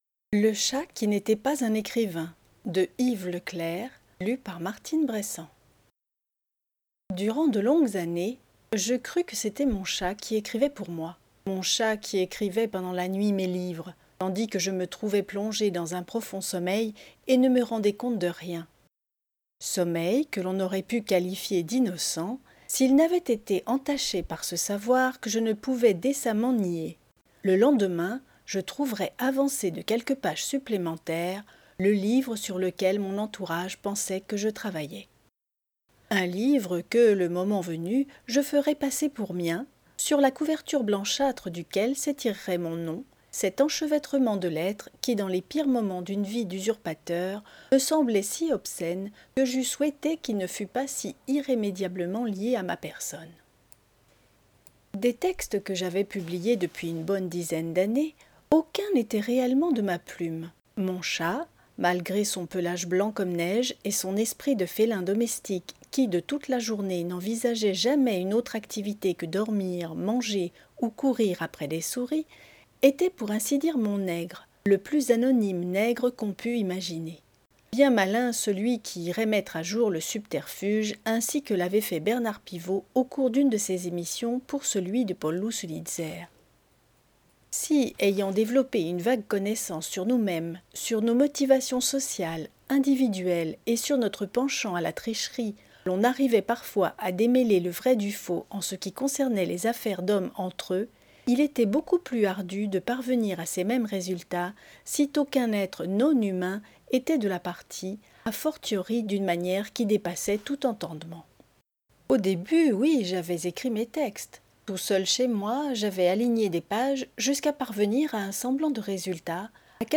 Lecture � haute voix-Le chat qui n'�tait pas un �crivain